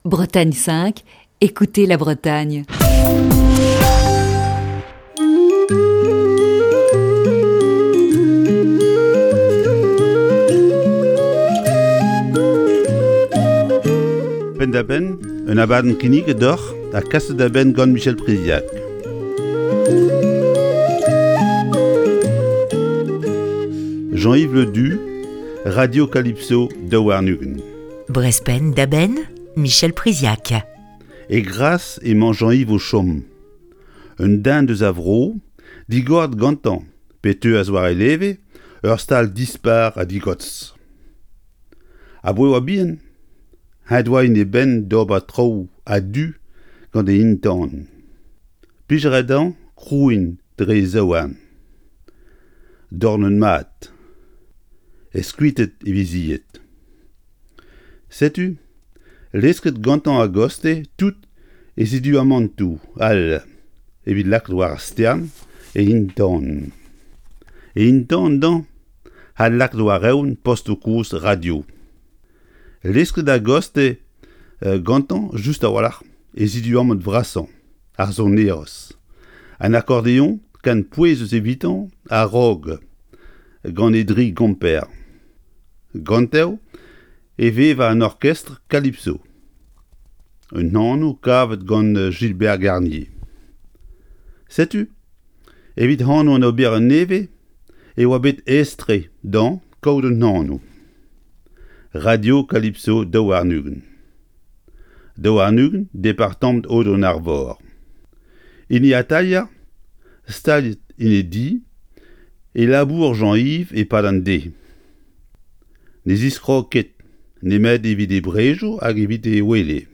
Chronique du 3 juin 2021.